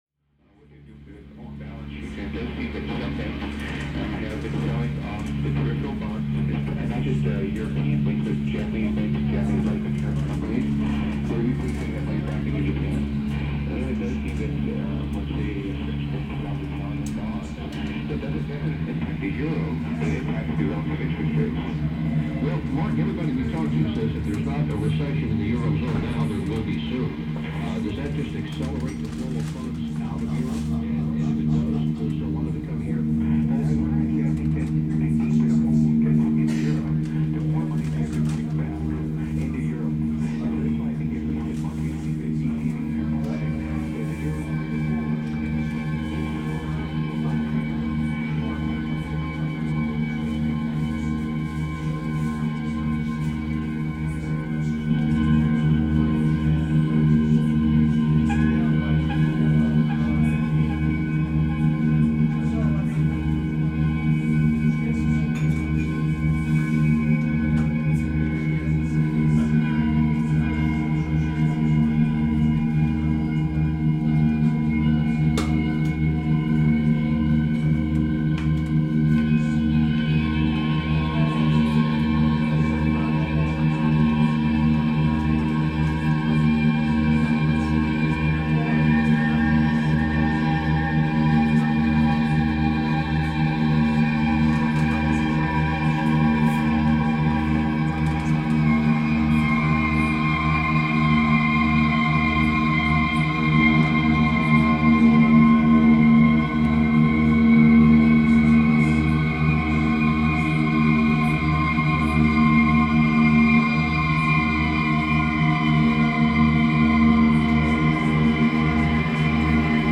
performance at Spotty Dog Books & Ale.